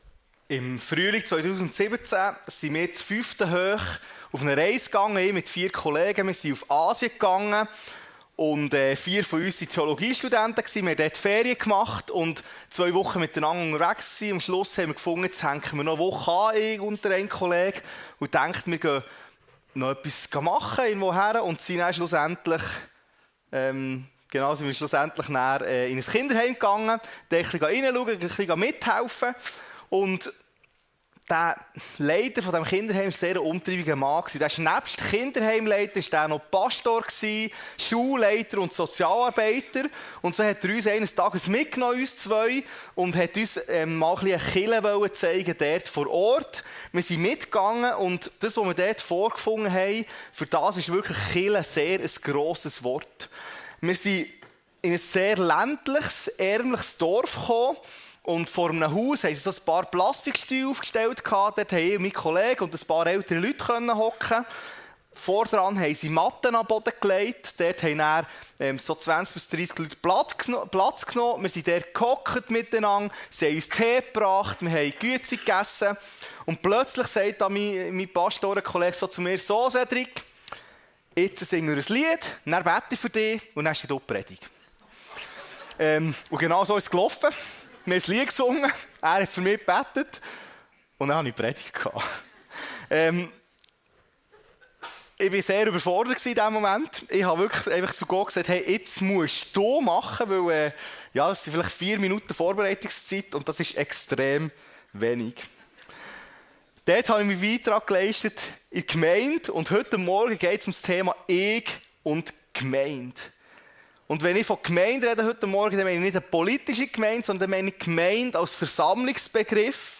Kirche & Glaube | Ich und die Gemeinde - seetal chile Predigten